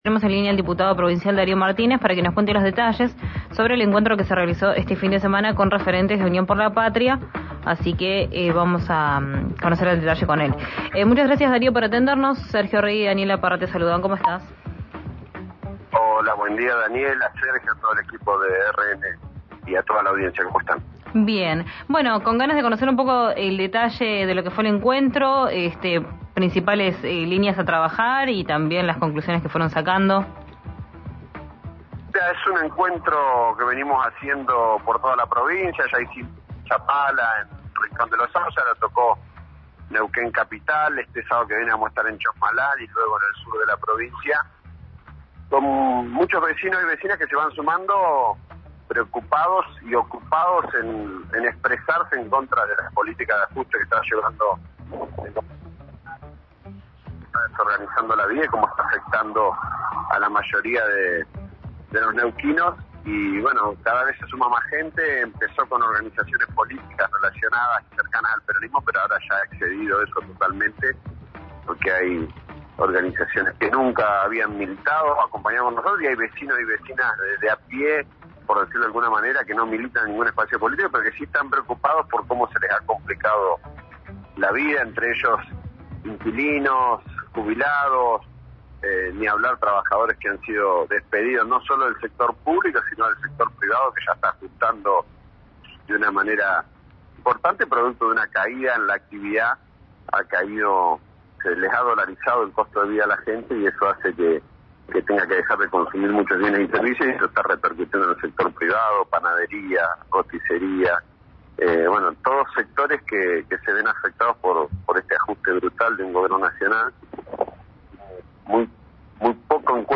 Escuchá a Darío Martínez, diputado de Unión por la Patria, en RÍO NEGRO RADIO: